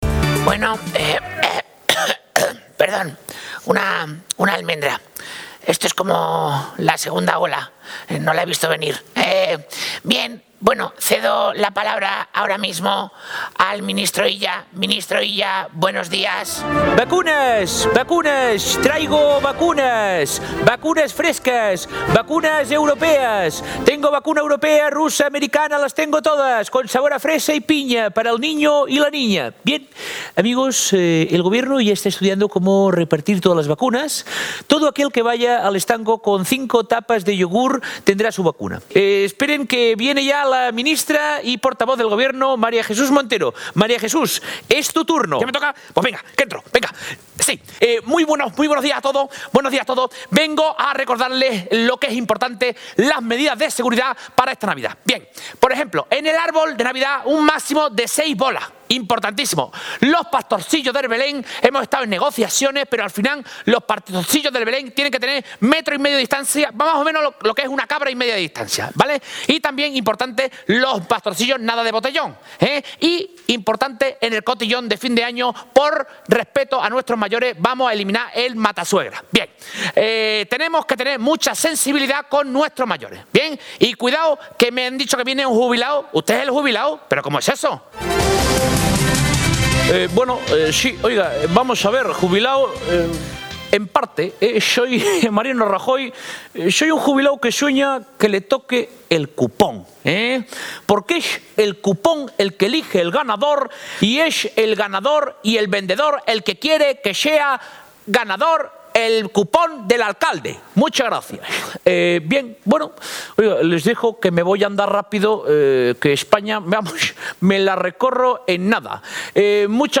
Entre tanto, el popular Latre dio una magnífica muestra de su portentoso talento como imitador.
políticos formato MP3 audio(2,46 MB) que, debido a la pandemia, ya nos hemos acostumbrado a ver en los medios de comunicación diariamente.